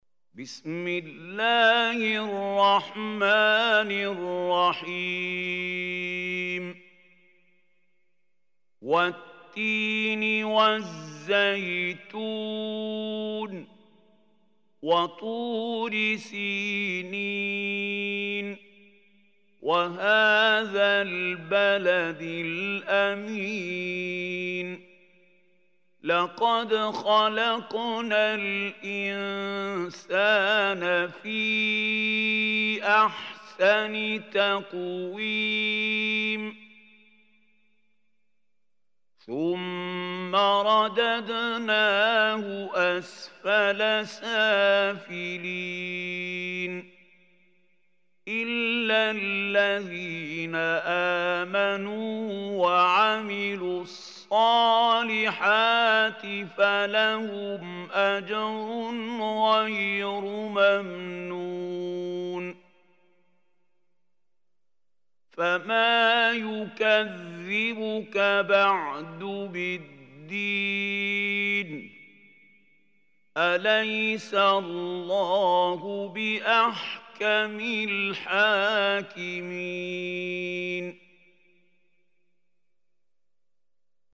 Surah Tin MP3 Recitation by Mahmoud Khalil
Surah At Tin is 95 surah of Holy Quran. Listen or play online mp3 tilawat/ recitation in Arabic in the beautiful voice of Sheikh Mahmoud Khalil Hussary.